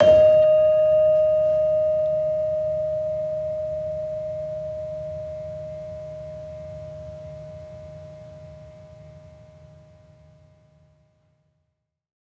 Gamelan Sound Bank
Gender-1-D#4-f.wav